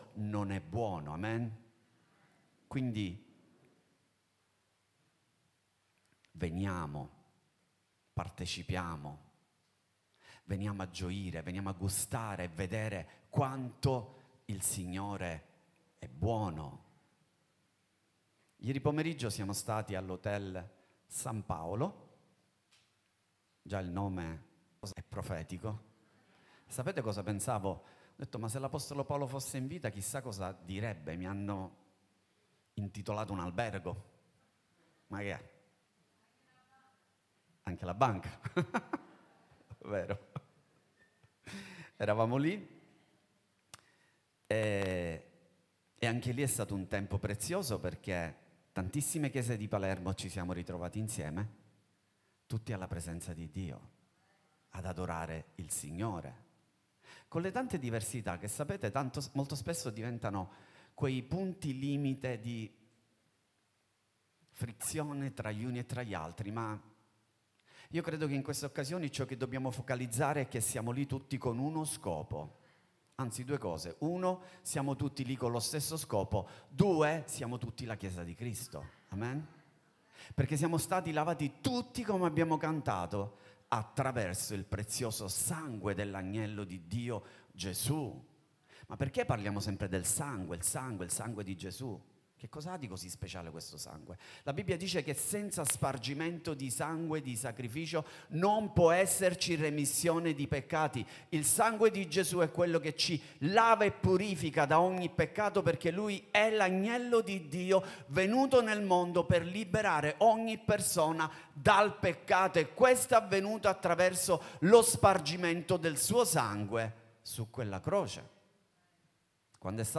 Worship Service